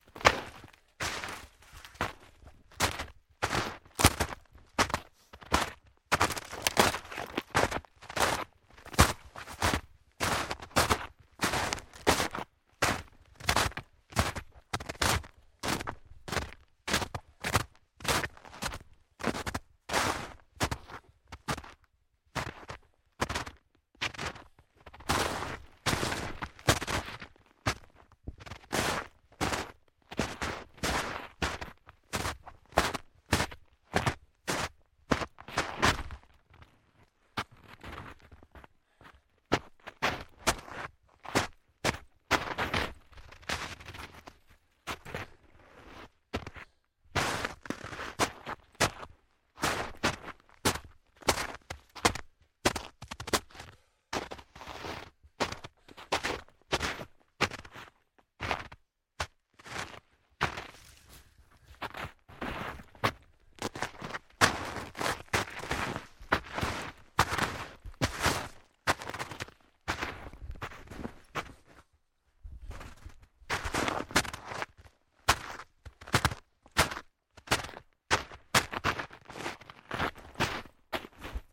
冬天 " 脚步声 重型橡胶靴 森林深层融化的结壳雪 重型慢速 中速
描述：脚步声厚重的橡胶靴森林深厚的硬壳硬壳雪重的慢速中速.flac
Tag: 脚步 靴子 森林 橡胶